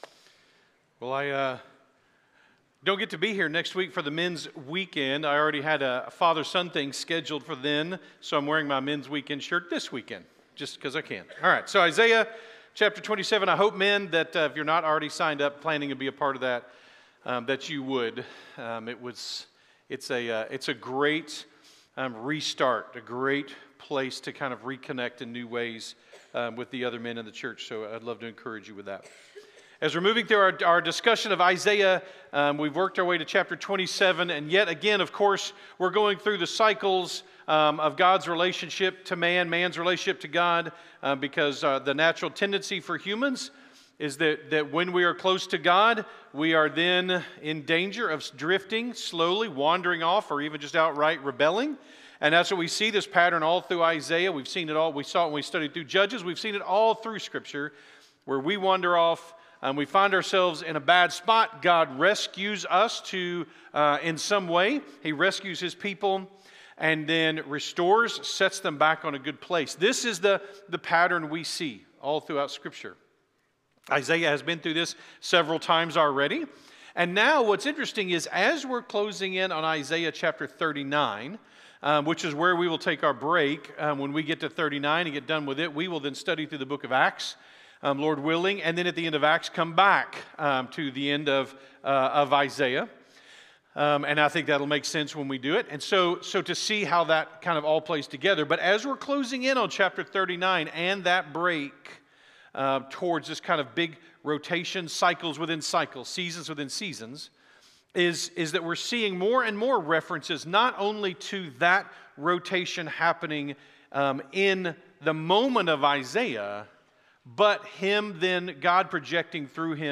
by South Spring Media | Apr 19, 2026 | 2026 Sermons, Isaiah, Isaiah Series | 0 comments